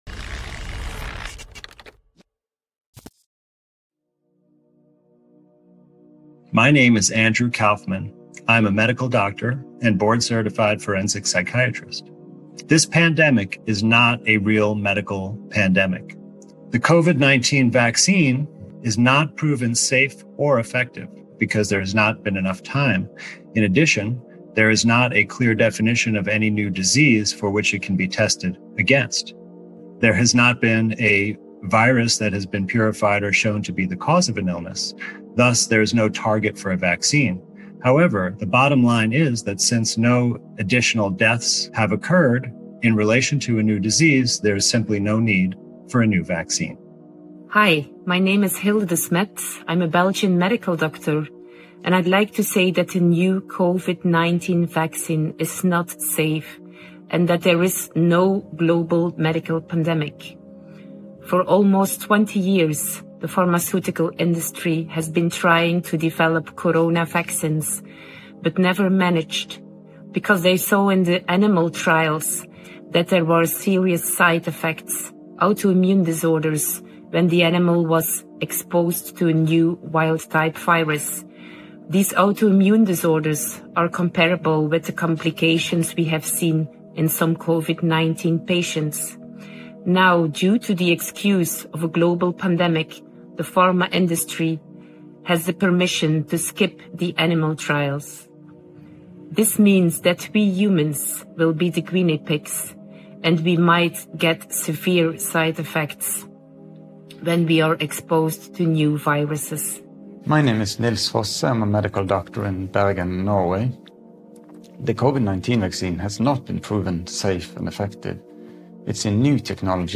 A panel of medical experts with huge experience all over the world express their concerns over the covid vaccine and the whole pandemic.